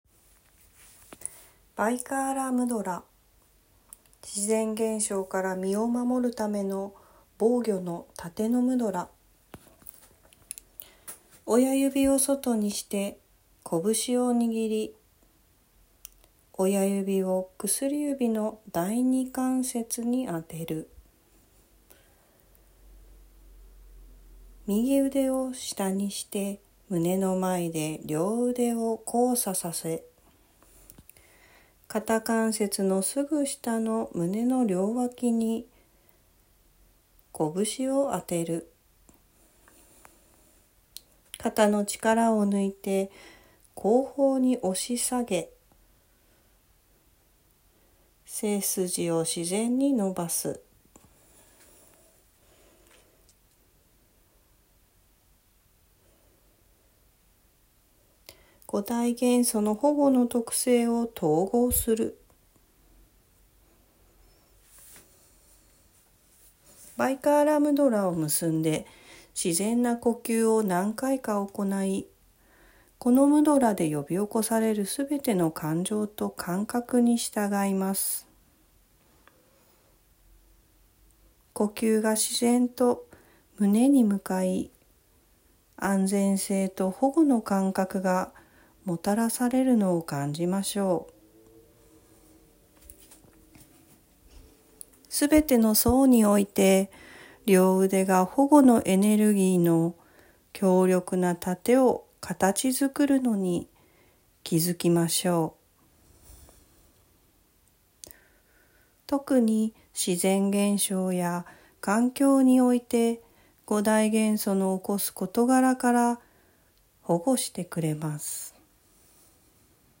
今回は五大元素の保護の性質を自分のエネルギーフィールドに統合する瞑想です。
3. 　肩を押し下げてあとは音声に従ってください